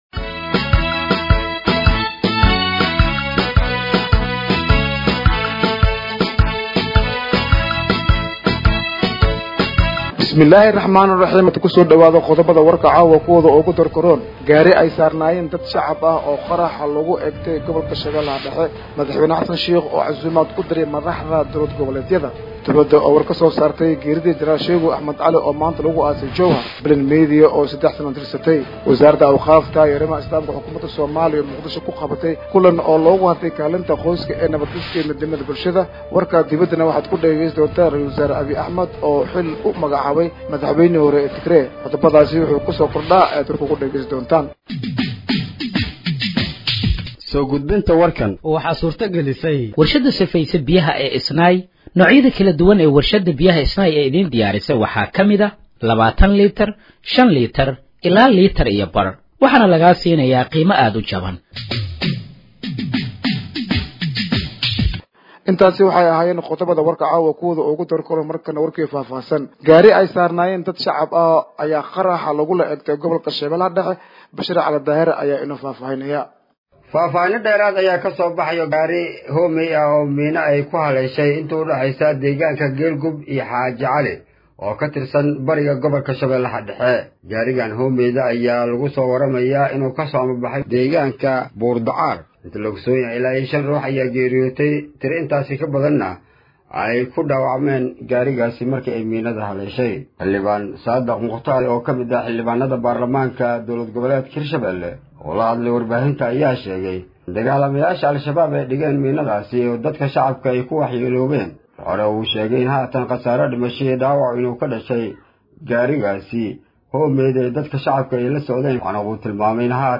Dhageeyso Warka Habeenimo ee Radiojowhar 11/04/2025
Halkaan Hoose ka Dhageeyso Warka Habeenimo ee Radiojowhar